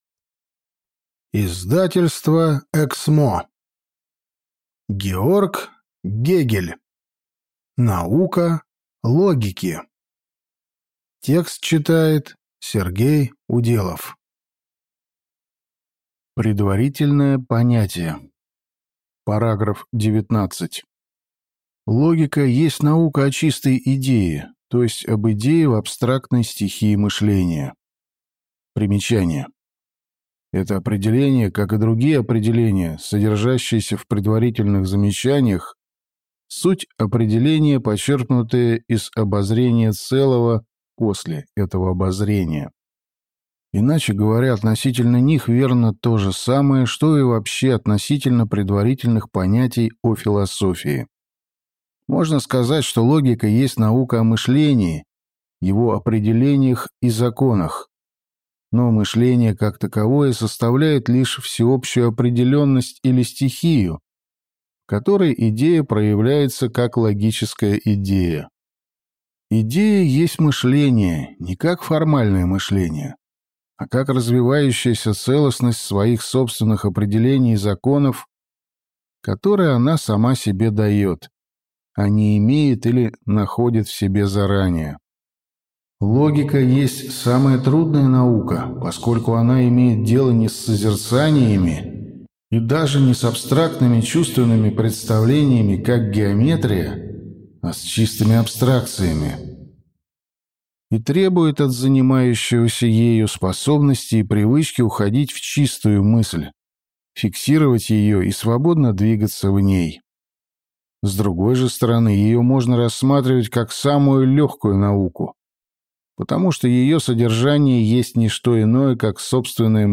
Аудиокнига Наука логики | Библиотека аудиокниг